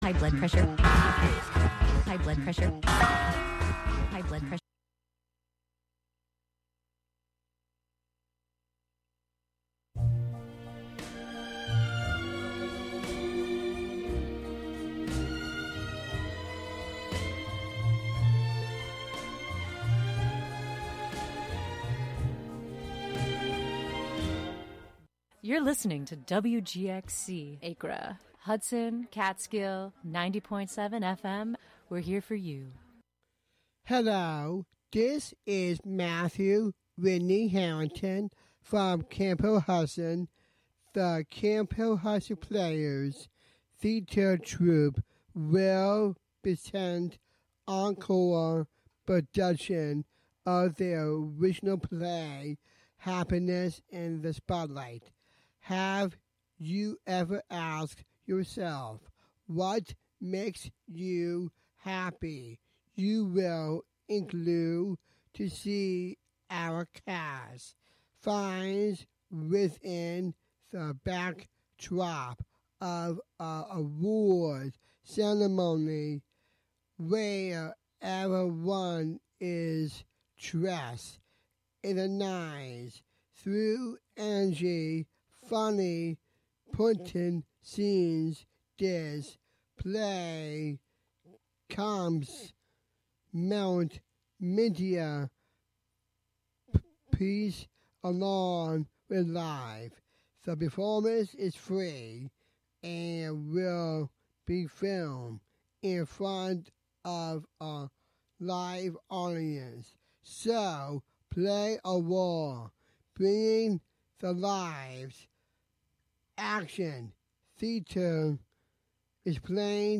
"Beakuency" invites the local community to be inspired by the joy, beauty, and wisdom bird enthusiasts find in the nature of our neighborhoods. Every episode features an interview with local bird people, plus a freeform mix of sound made by birds and humans inspired by birds, and “Birds of Wave Farm,” a field recording journal from Wave Farm, in Acra, New York.